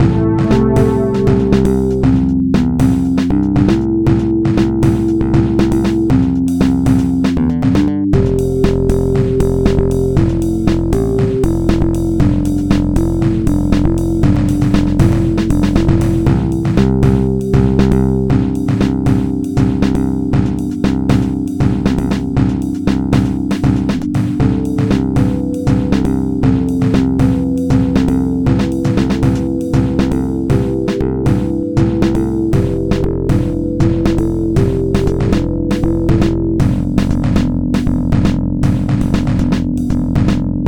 beautiful, Stereolab-style organ